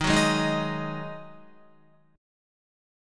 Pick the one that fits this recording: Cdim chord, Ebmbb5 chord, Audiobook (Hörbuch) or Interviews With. Ebmbb5 chord